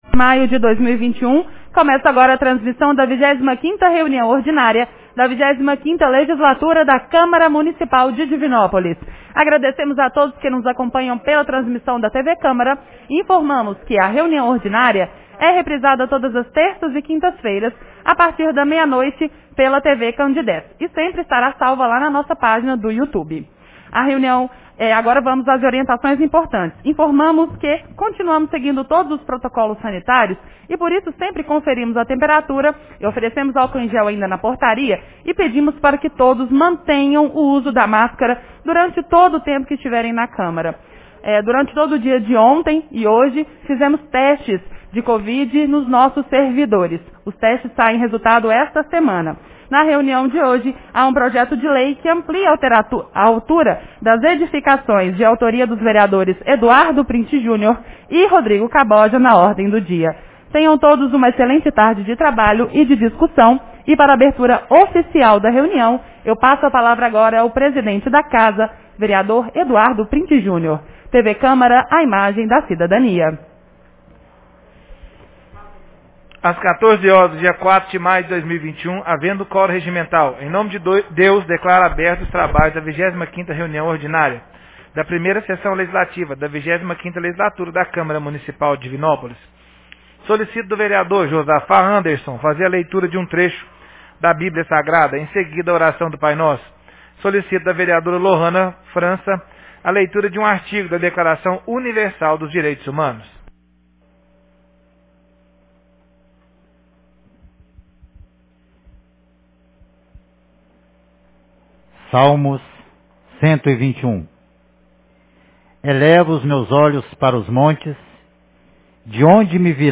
Reunião Ordinária 25 de 04 de maio 2021